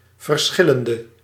Ääntäminen
France (Paris): IPA: [di.vɛʁ]